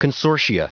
Prononciation du mot consortia en anglais (fichier audio)
Prononciation du mot : consortia